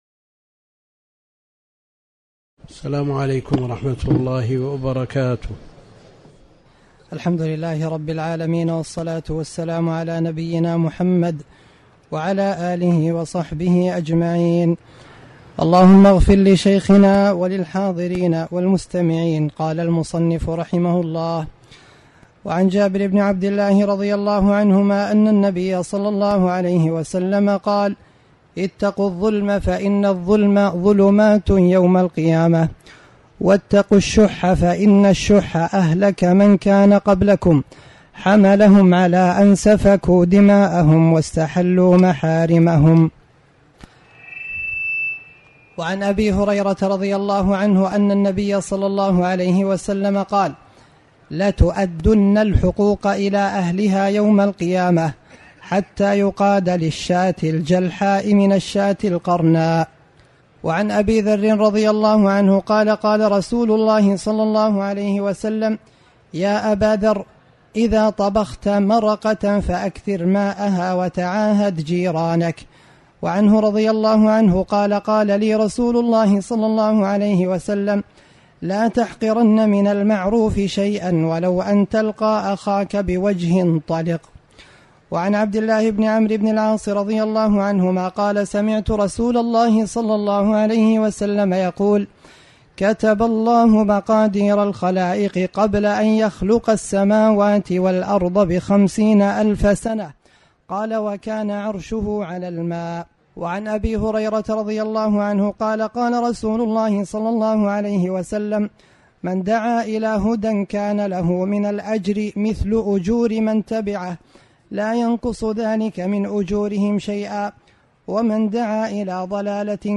تاريخ النشر ٧ ذو القعدة ١٤٣٩ هـ المكان: المسجد الحرام الشيخ: فضيلة الشيخ د. عبد الكريم بن عبد الله الخضير فضيلة الشيخ د. عبد الكريم بن عبد الله الخضير كتاب الجامع